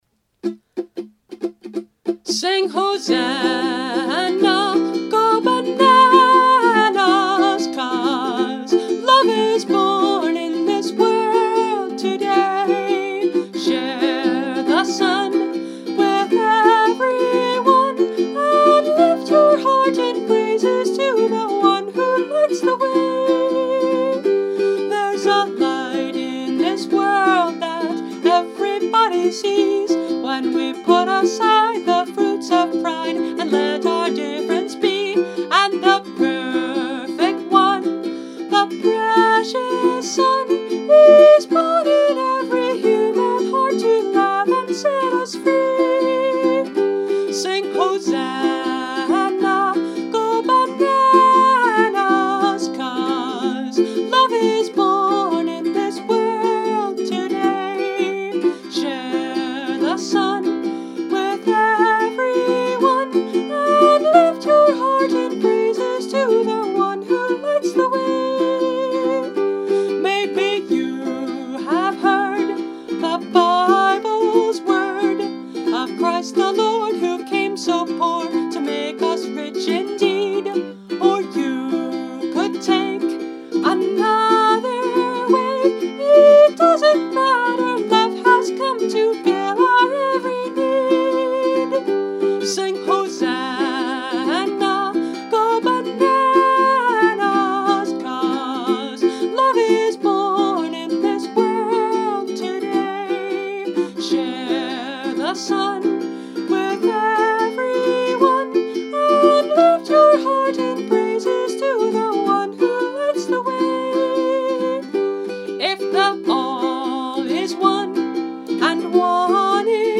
Instrument: Brio – Red Cedar Concert Ukulele